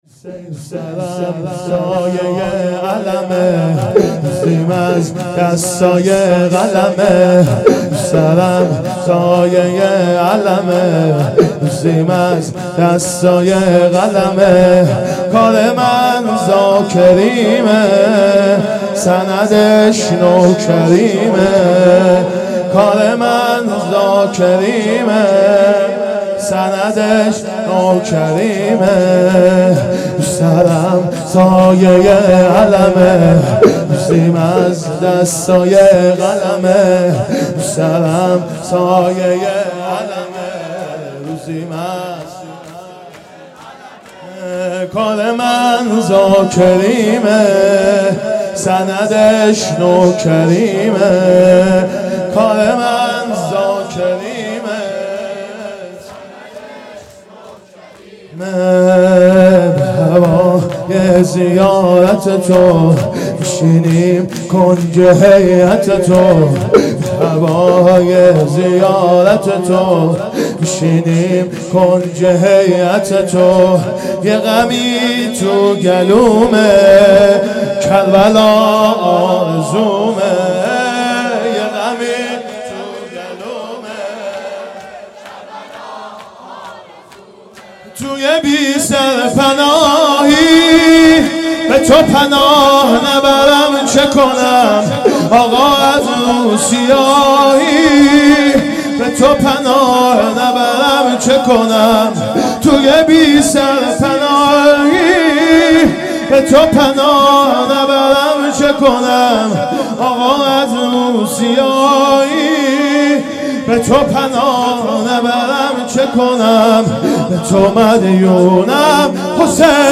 محرم 1440 _ شب چهارم